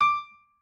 pianoadrib1_29.ogg